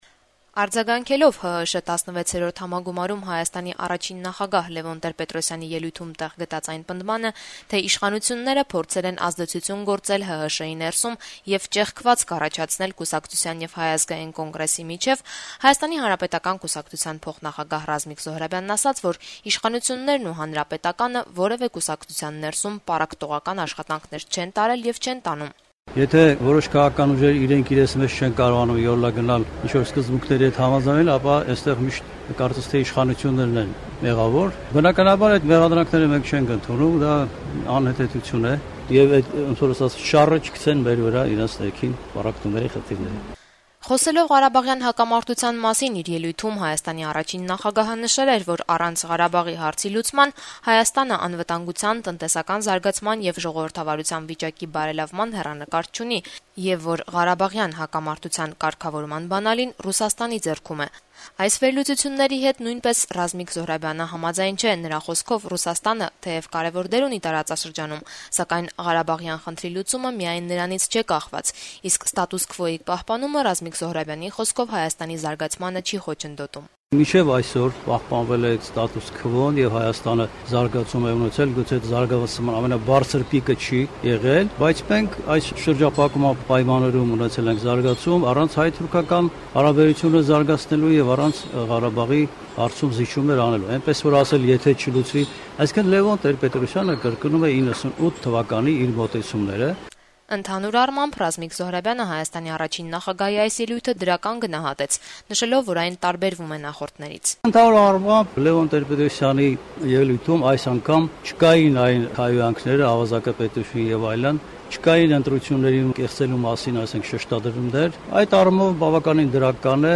Հայաստանի խորհրդարանական քաղաքական ուժերի ներկայացուցիչները երկուշաբթի օրը «Ազատություն» ռադիոկայանի հետ զրույցներում իրենց կարծիքները հնչեցրին շաբաթավերջին ՀՀՇ-ի համագումարում Լեւոն Տեր-Պետրոսյանի ելույթի վերաբերյալ: